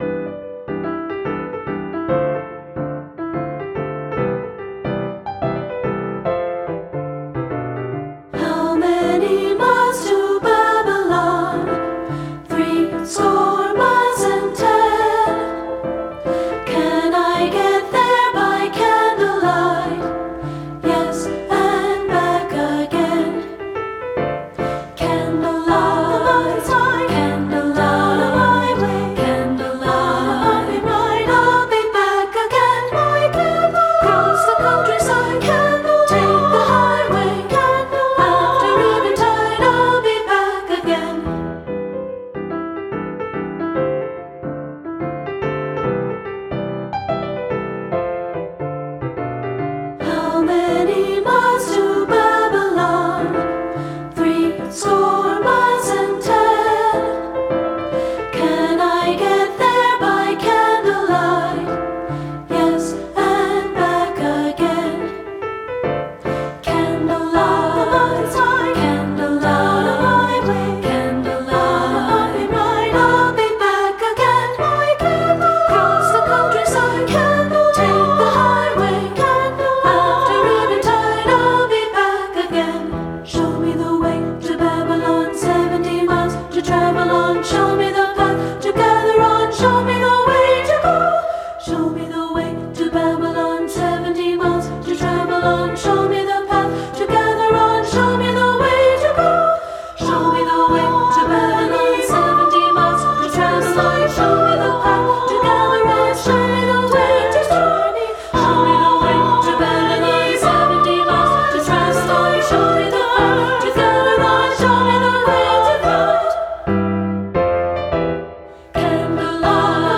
• Soprano
• Alto
• Piano
Studio Recording
Playful, jazzy, and immensely fun to learn and sing
with a clever melody
Ensemble: Treble Chorus
Key: E dorian
Tempo: Swing the eights (q = 100)
Accompanied: Accompanied Chorus